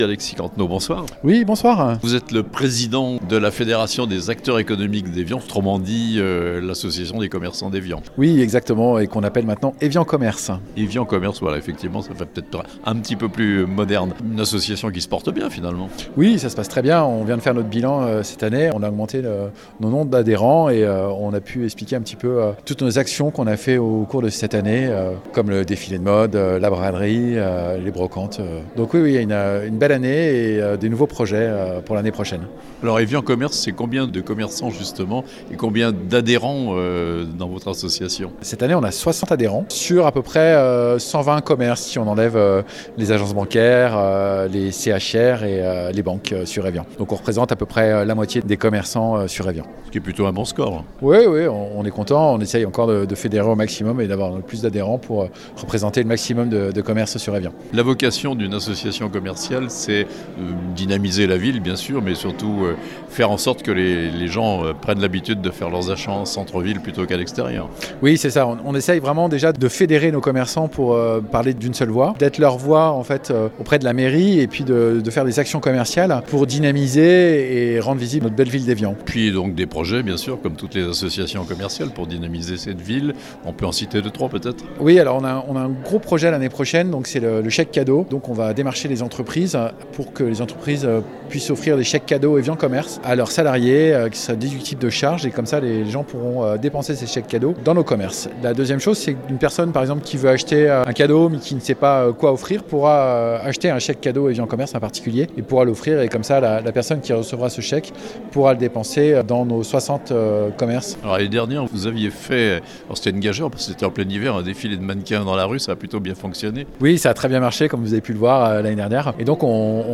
Un bureau en partie renouvelé pour l'association Evian Commerces (interview)